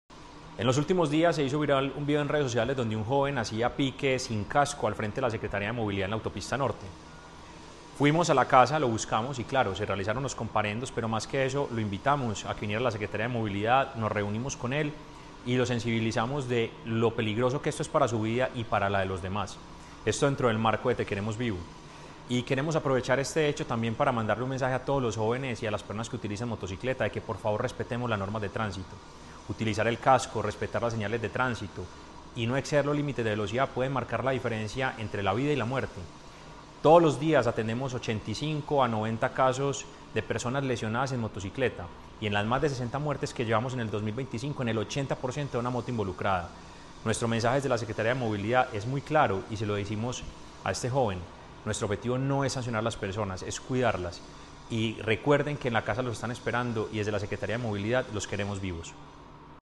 Palabras de Mateo González Benítez, secretario de Movilidad